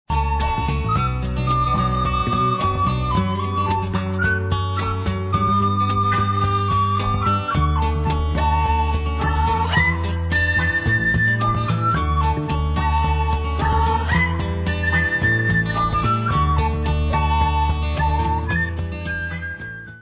Spaghetti western magic from the meastro